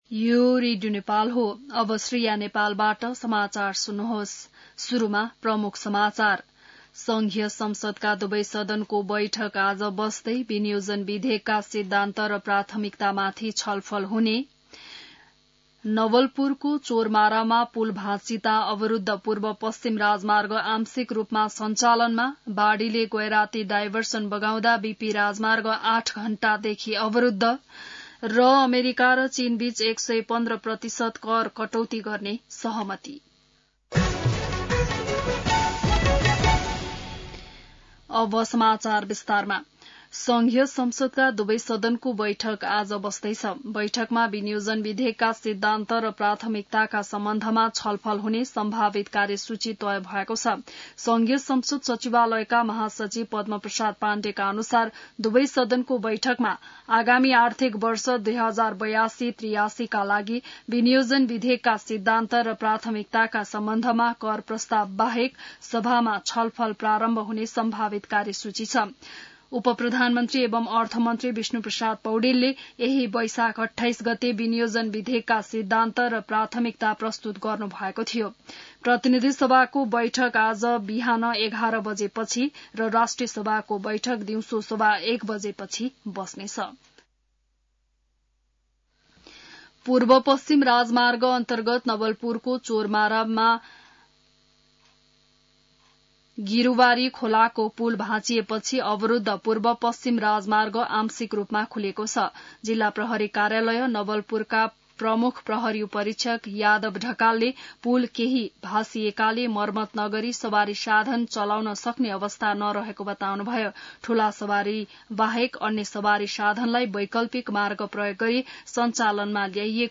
बिहान ९ बजेको नेपाली समाचार : ३० वैशाख , २०८२